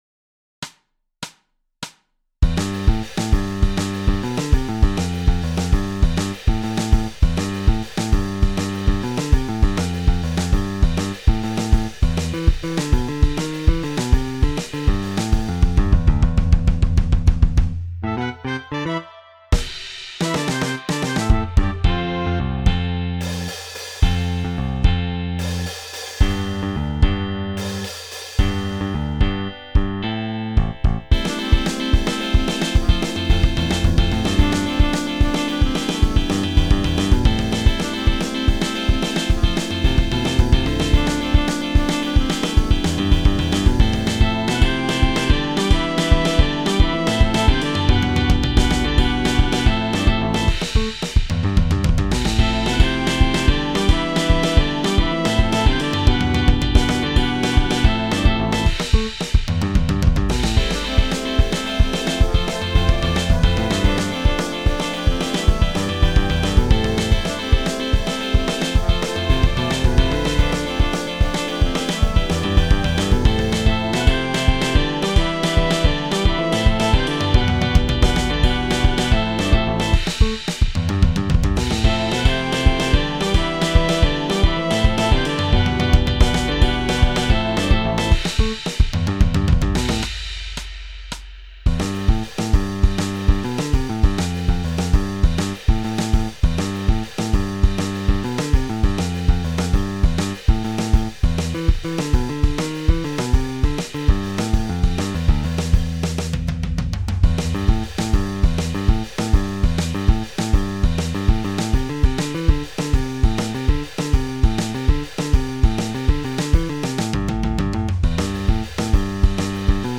기악곡이에요.